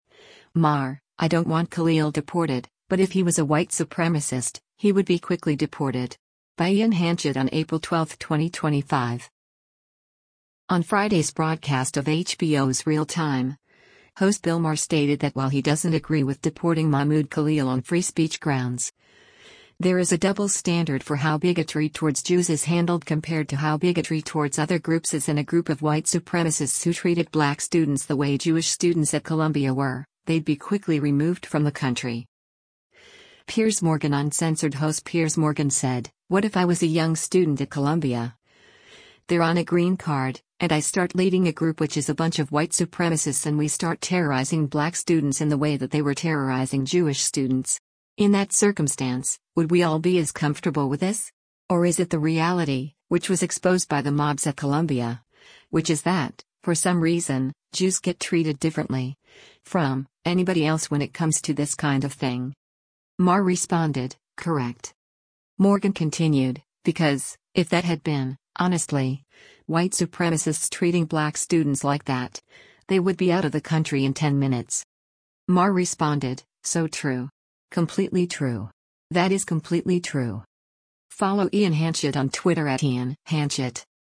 On Friday’s broadcast of HBO’s “Real Time,” host Bill Maher stated that while he doesn’t agree with deporting Mahmoud Khalil on free speech grounds, there is a double standard for how bigotry towards Jews is handled compared to how bigotry towards other groups is and a group of white supremacists who treated black students the way Jewish students at Columbia were, they’d be quickly removed from the country.